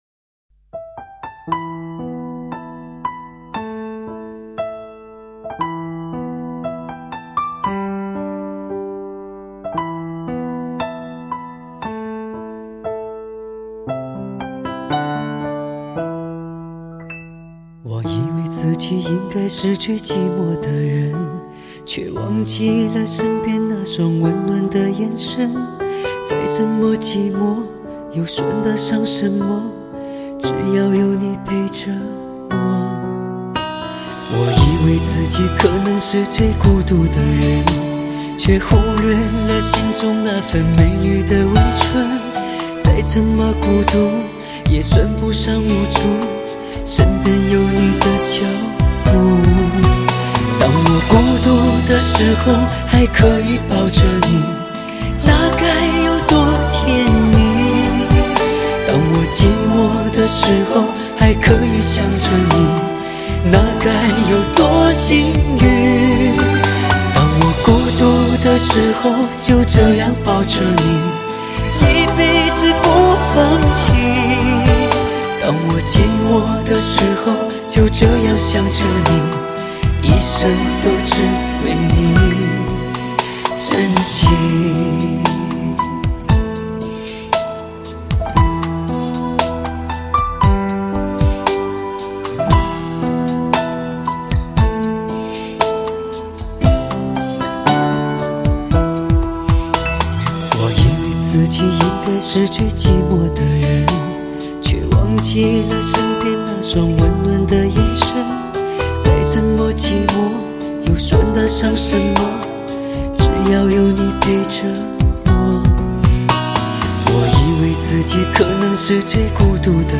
情歌王子16首经典流行金曲精选专辑黑胶CD发烧碟。